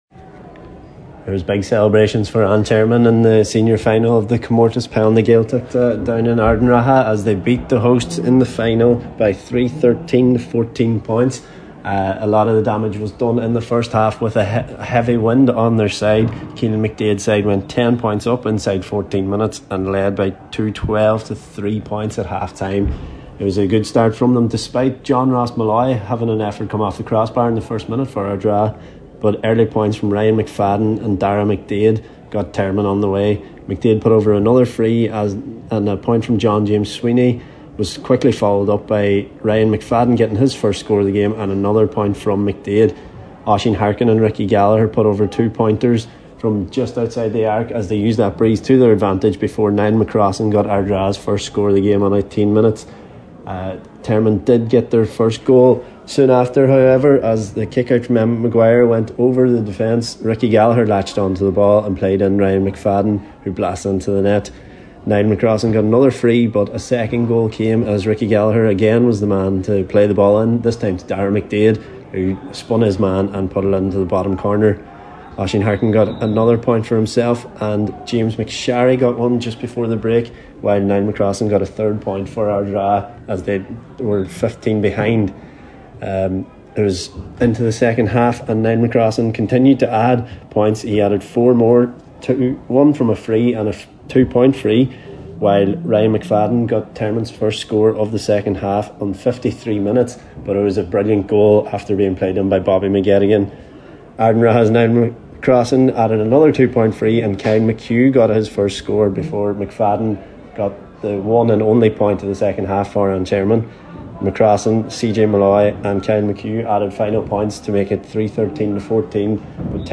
Report.mp3